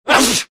Звуки чихания
Звук мужского чихания